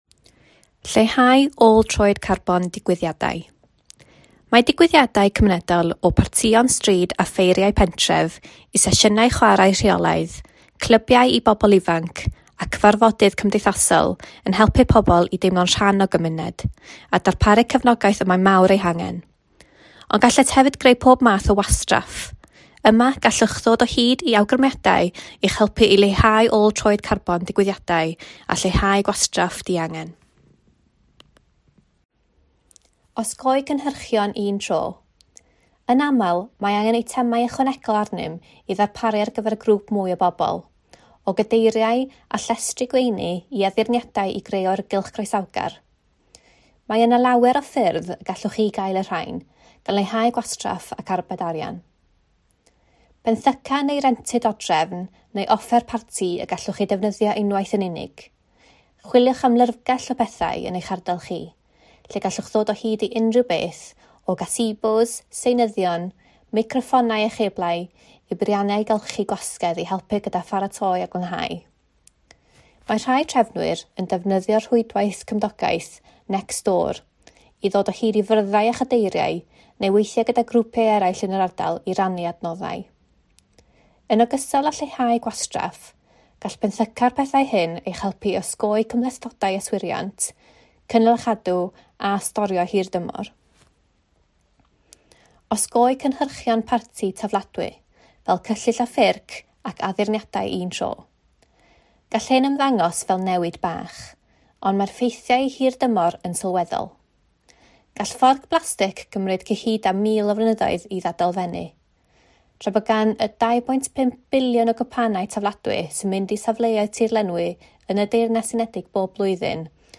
Gallwch wrando ar yr erthygl hon fel recordiad sain, sy’n para 19 munud a 44 eiliad, trwy glicio ar y botwm isod.